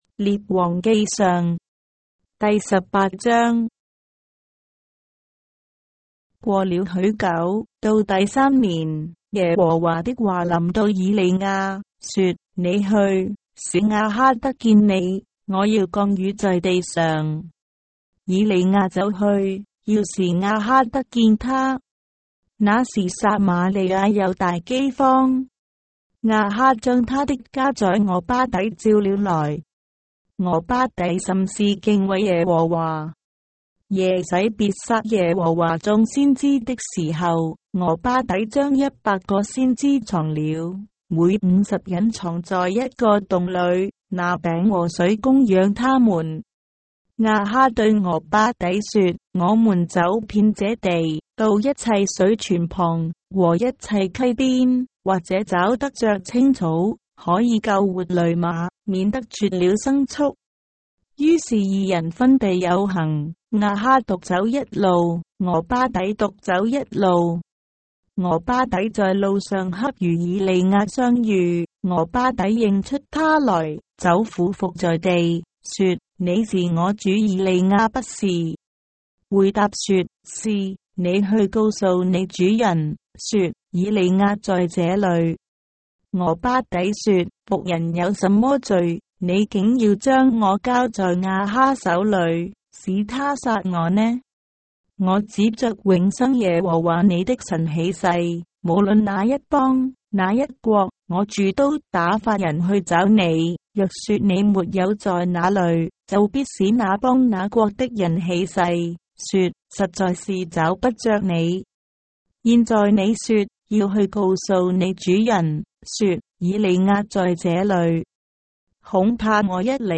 章的聖經在中國的語言，音頻旁白- 1 Kings, chapter 18 of the Holy Bible in Traditional Chinese